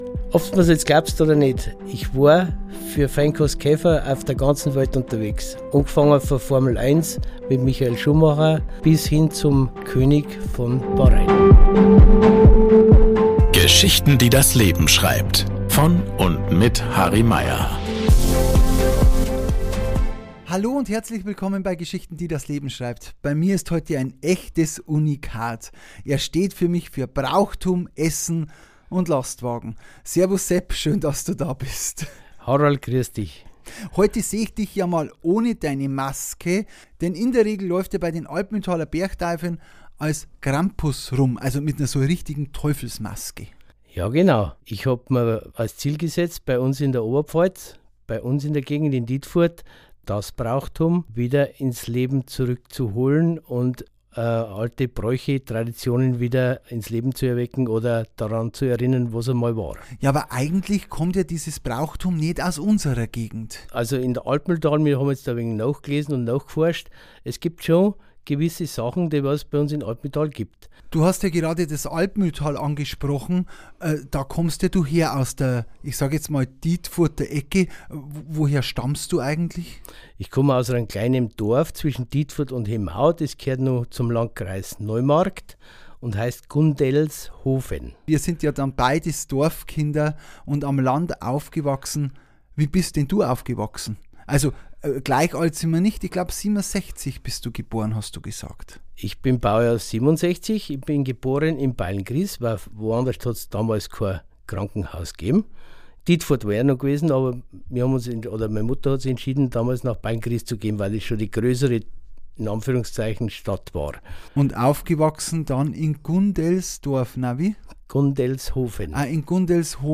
Ein spannendes Gespräch über Reisen, außergewöhnliche Erlebnisse, gutes Essen und die Rückkehr zu den Wurzeln.